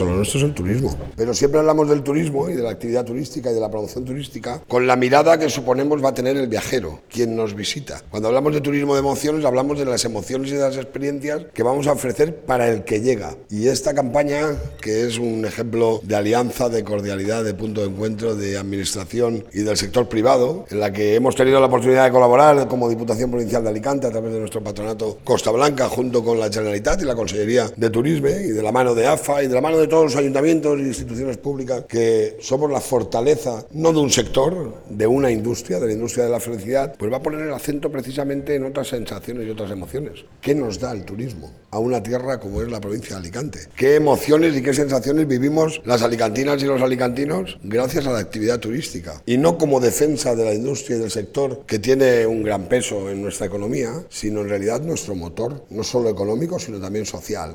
Campana-Costa-Blanca-ESOQUETUMEDAS-Corte-Toni-Perez.mp3